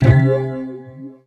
41 SYNT01 -R.wav